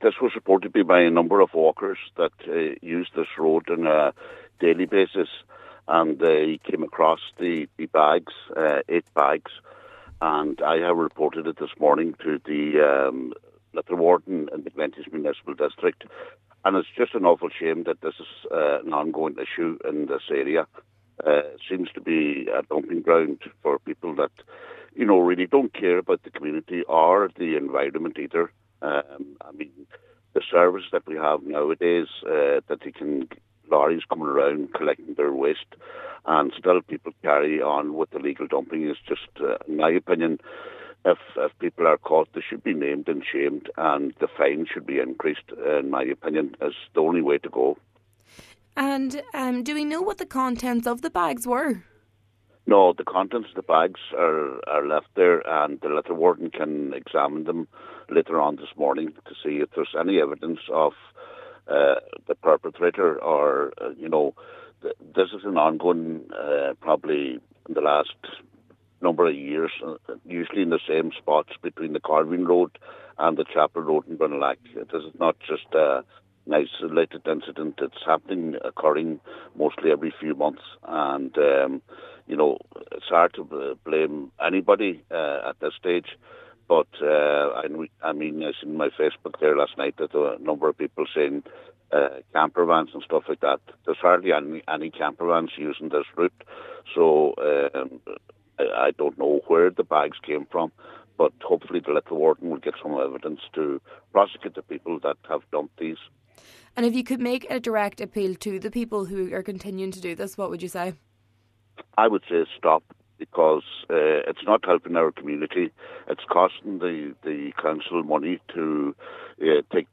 Cllr O’Fearraigh named the area a black spot and made the following appeal to the perpetrators: